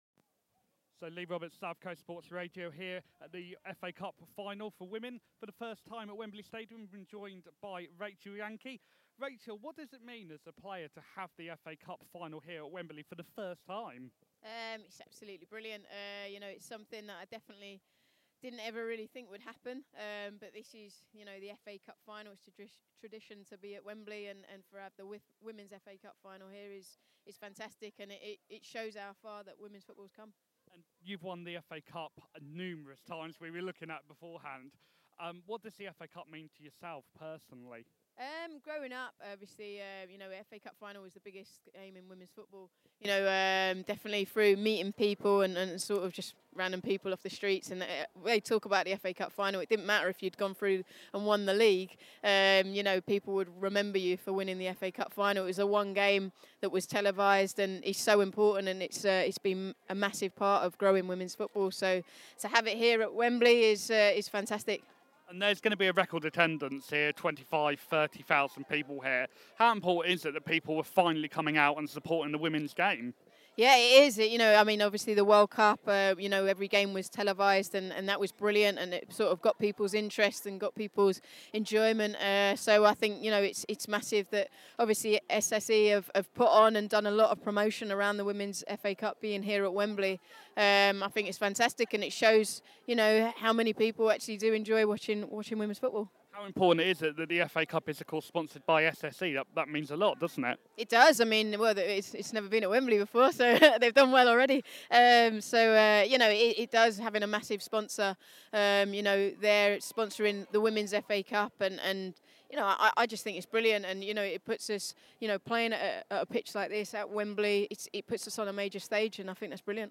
Rachel Yankey interview